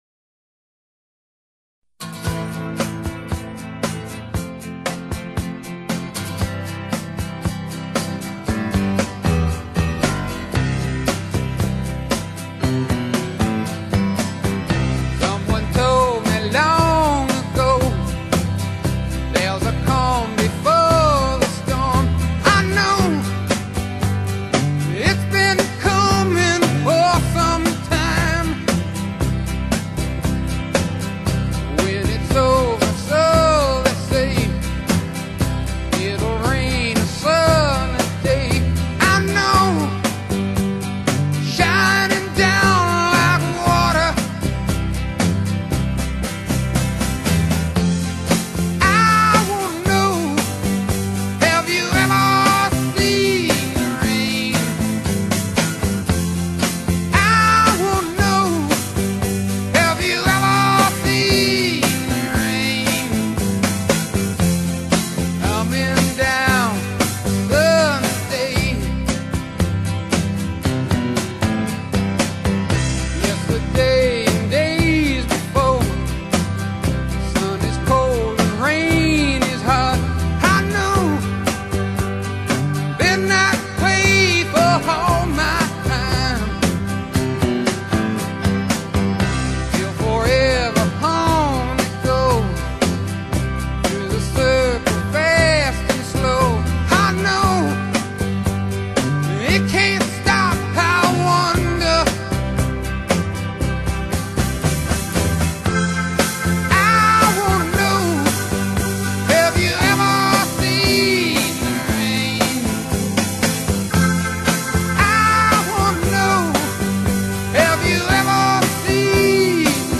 rock group